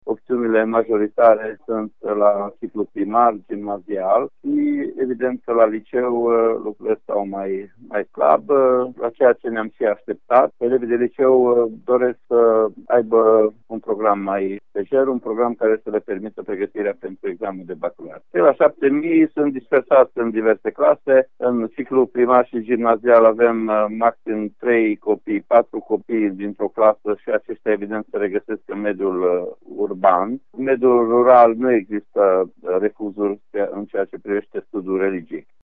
Inspectorul școlar general al județului Mureș, Ștefan Someșan, a arătat că cele mai multe opțiuni pro religie au fost în mediul rural și la ciclul gimnazial.